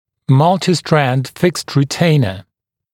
[‘mʌltɪˌstrænd fɪkst rɪ’teɪnə][‘малтиˌстрэнд фикст ри’тэйнэ]многопрядевый несъемный ретейнер